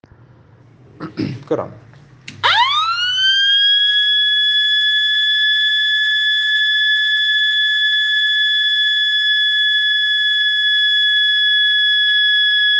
Dual Tone Hooter : 80dB Sound with Dual Tone with adjustable volume knob in 92x92 mm
2Volume80 dB max. adjustable with knob on back side
ESD-Dual-Tone-Hooter-Tone-1.mp3